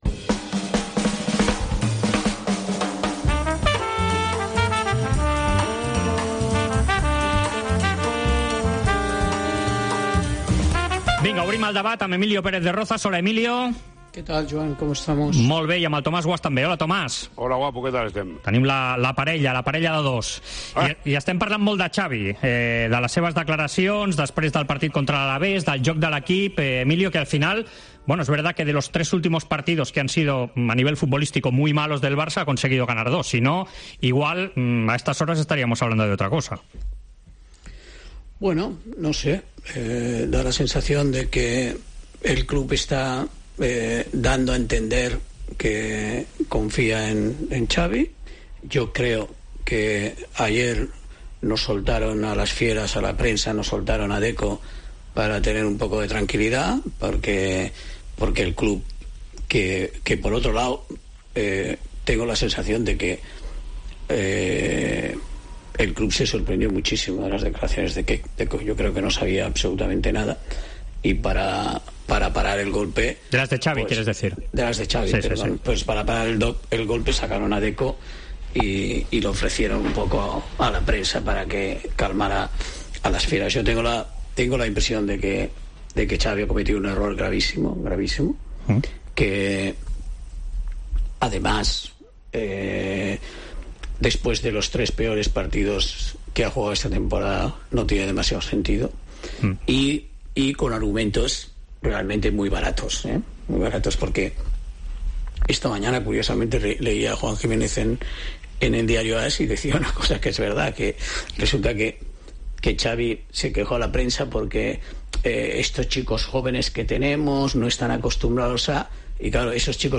AUDIO: Els dos col·laboradors de la Cadena COPE repassen l'actualitat esportiva de la setmana.